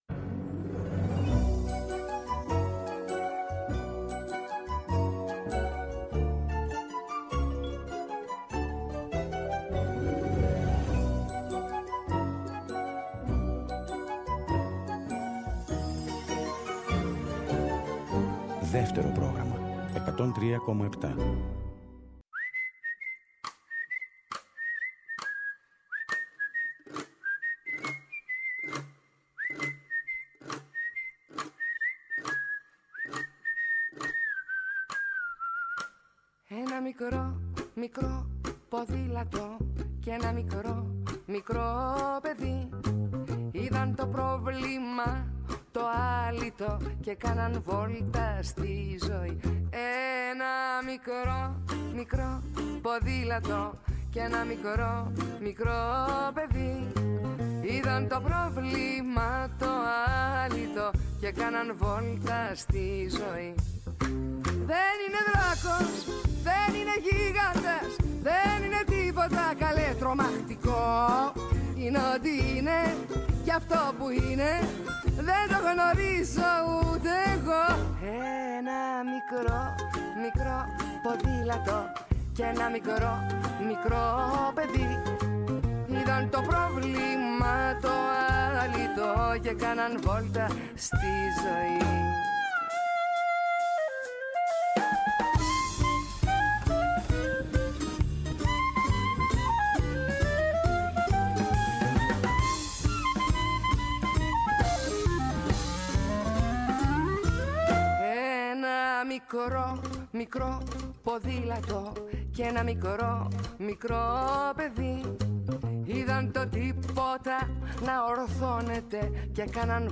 ραδιοφωνική εκπομπή